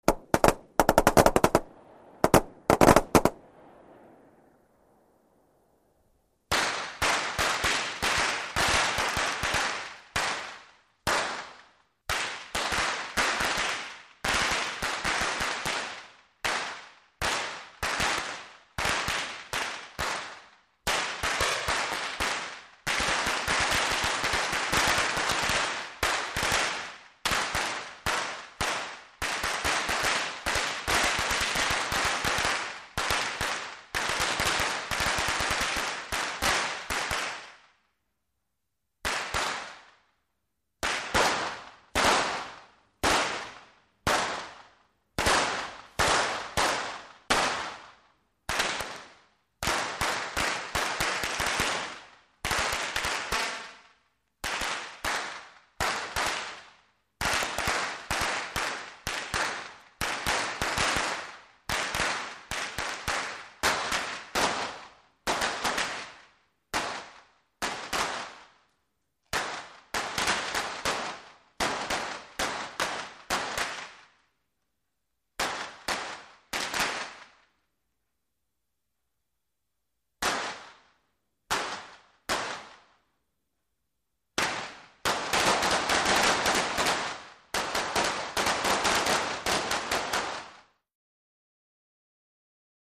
Large Firecrackers, W Quick Multiple Explosions, Interior Perspective.